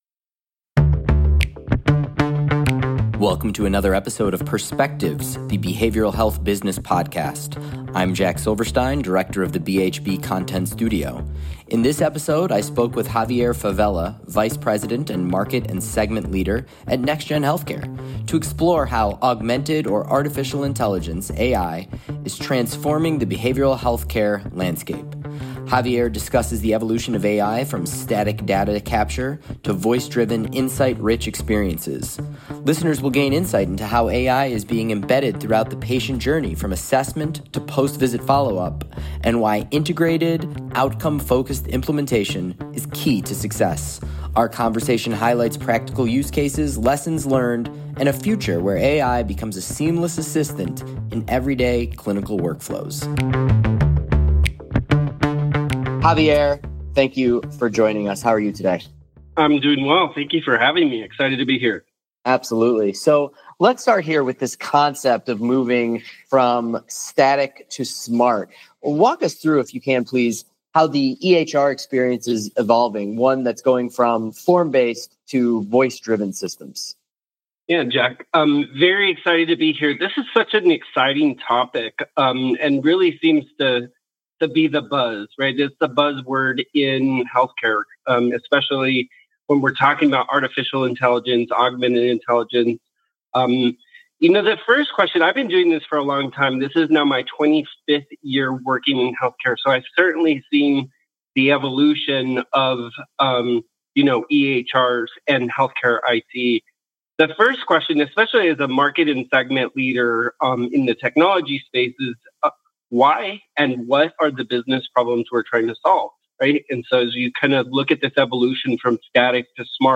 Today's conversation highlights practical use cases, lessons learned, and a future where AI becomes a seamless assistant in everyday clinical workflows.